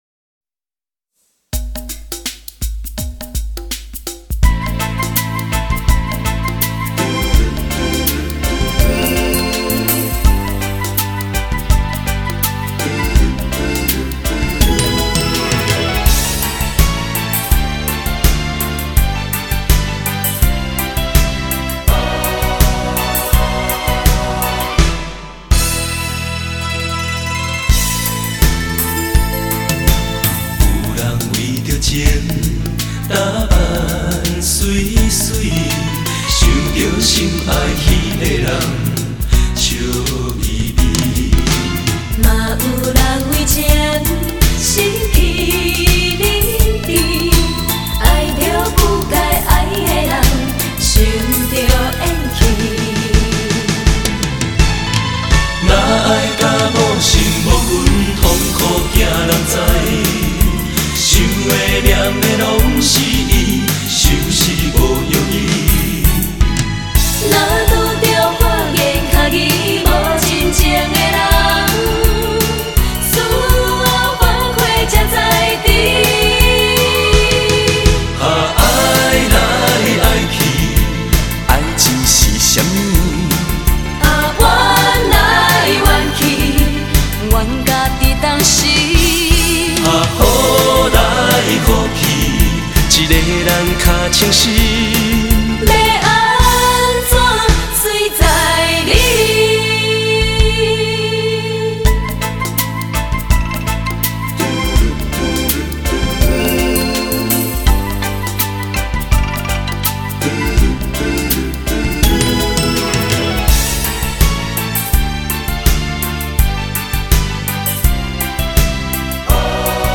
万中选一，每首歌蕴含无比丰富的情感与温暖，时而慷慨激昂，时而轻妙悠扬。
优美、纯净的美声特质与歌曲曼妙的旋律创造了最完美的融合。